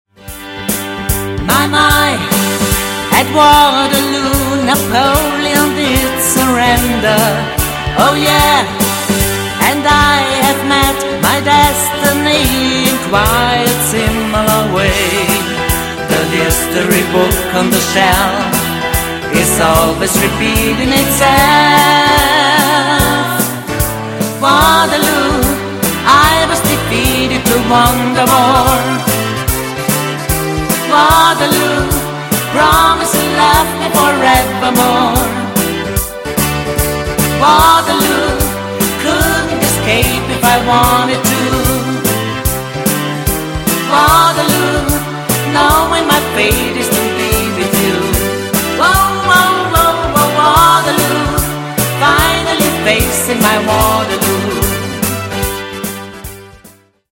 70ER – 90ER